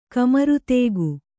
♪ kamarutēgu